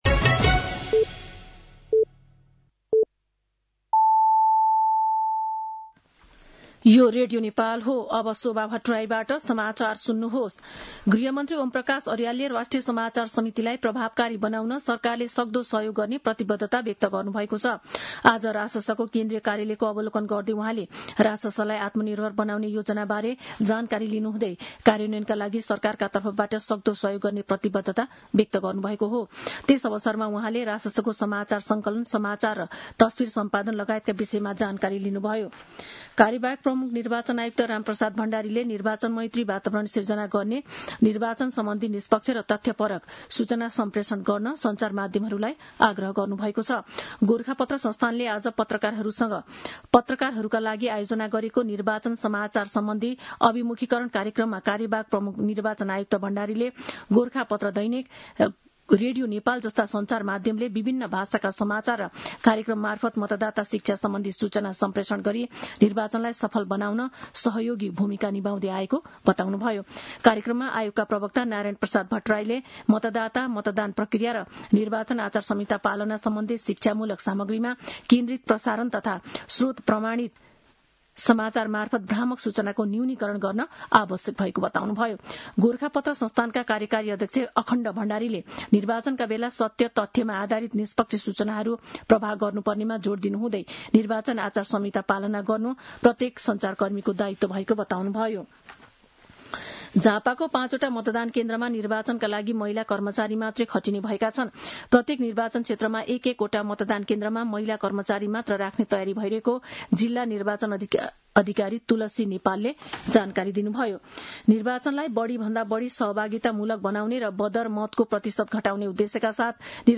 साँझ ५ बजेको नेपाली समाचार : १० फागुन , २०८२
5-pm-nepali-news-11-10.mp3